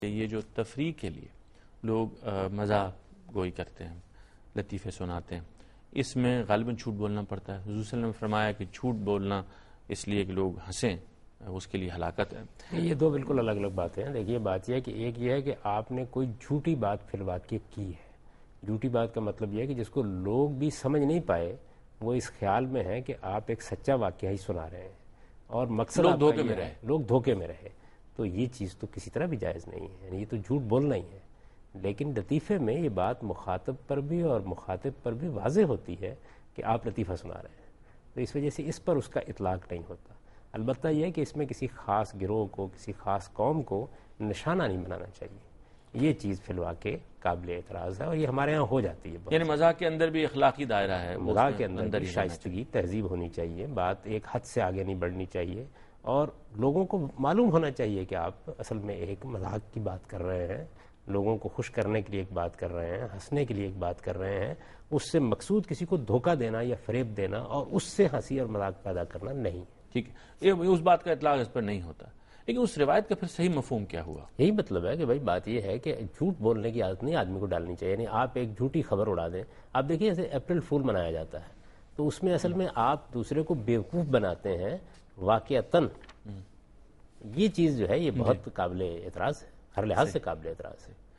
Category: TV Programs / Dunya News / Deen-o-Daanish / Questions_Answers /
دنیا نیوز کے پروگرام دین و دانش میں جاوید احمد غامدی ”مزاح گوئی اور جھوٹ“ سے متعلق ایک سوال کا جواب دے رہے ہیں